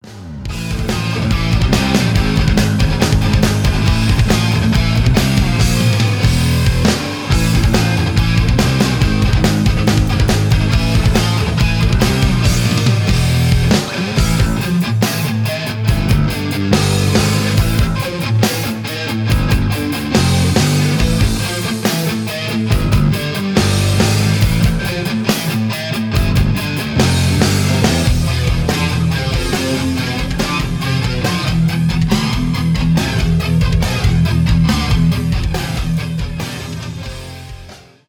без слов
heavy metal
инструментальные